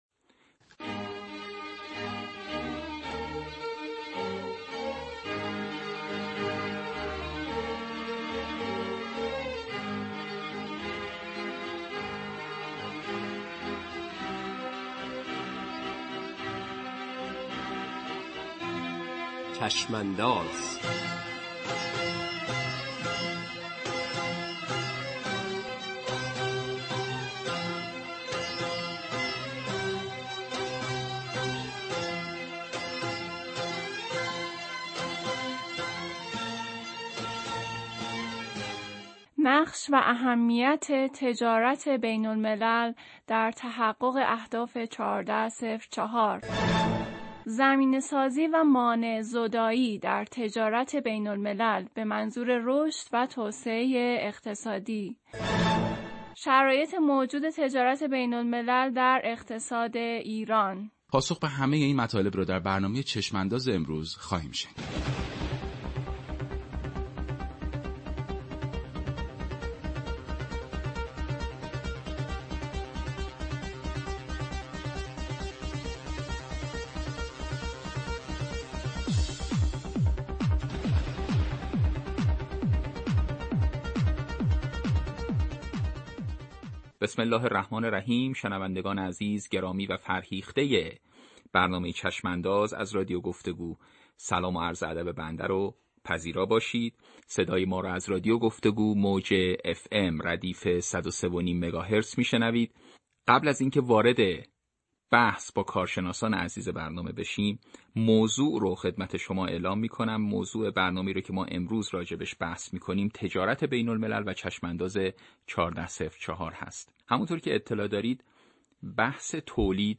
صوت گفتگوی رادیویی
رادیو-گفتگو-قدیری-ابیانه.mp3